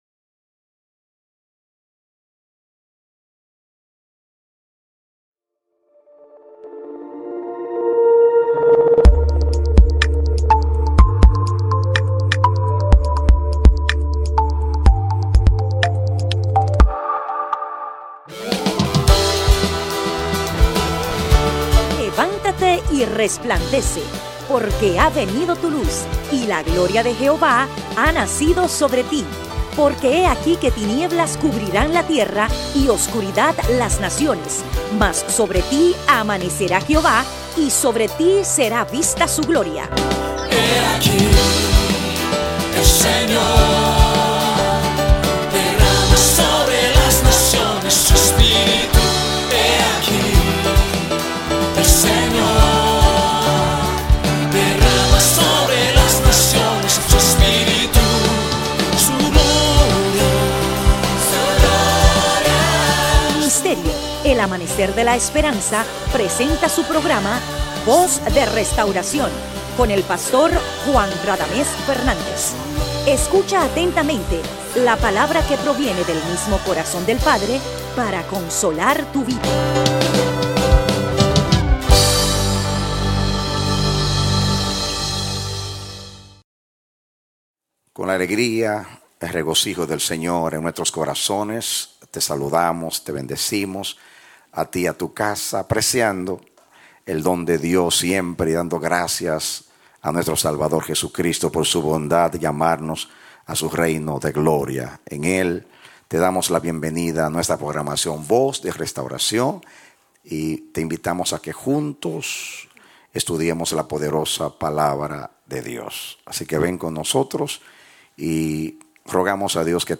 Prédicas – El Amanecer de la Esperanza Ministry